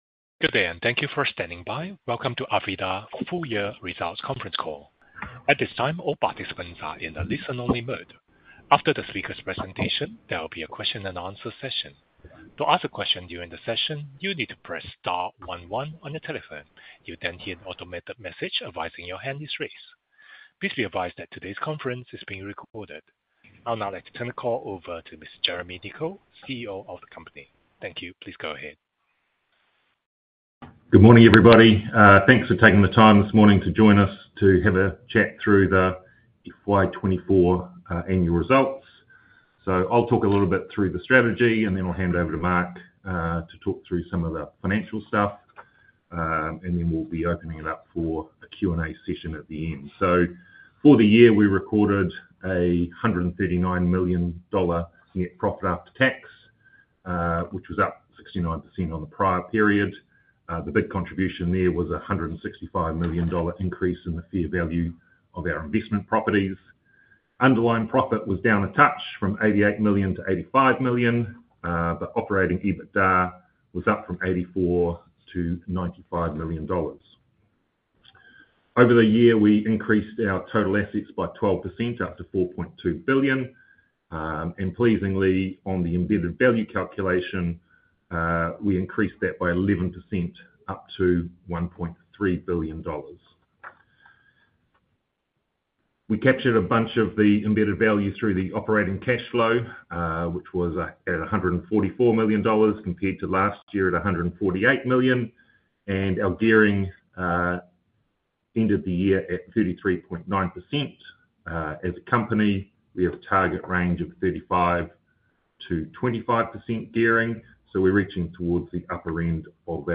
arvida-full-year-results-conference-call_38039.mp3